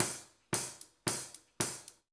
DWS HI-HAT-R.wav